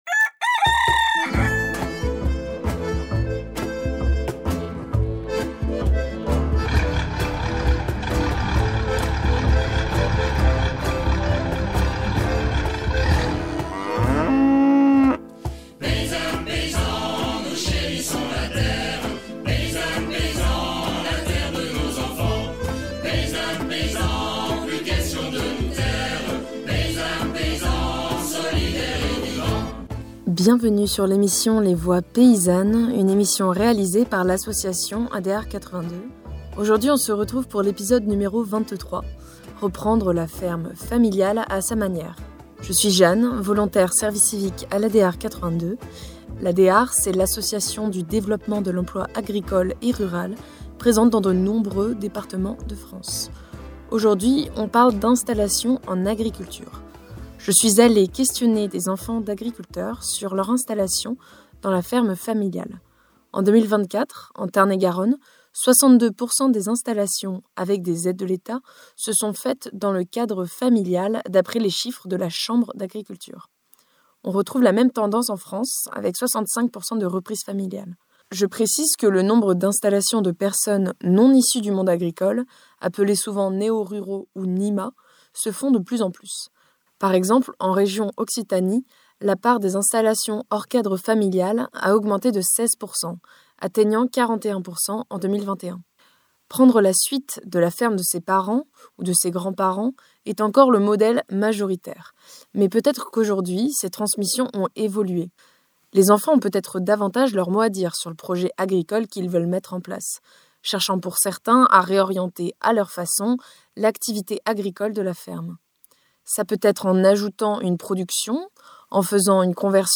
Dans cette émission, on part à la rencontre de deux agriculteurs qui ont repris la ferme de leurs parents.